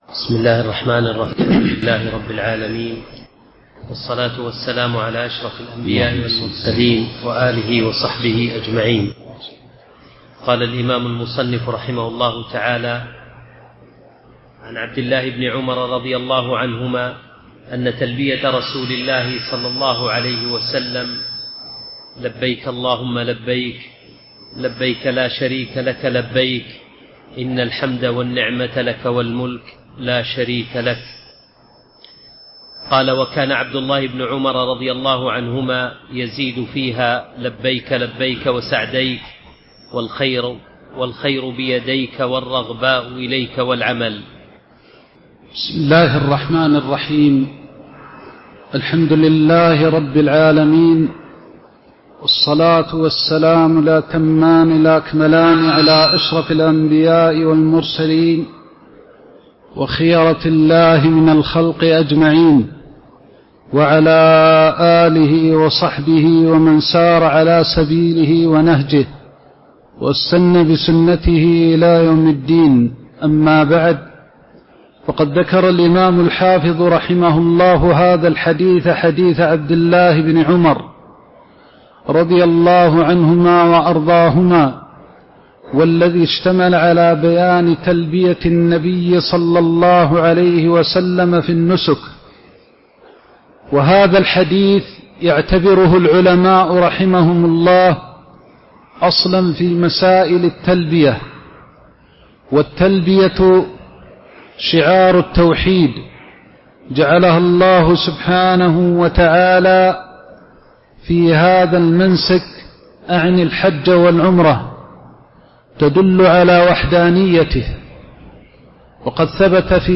تاريخ النشر ١٥ رمضان ١٤٤٦ هـ المكان: المسجد النبوي الشيخ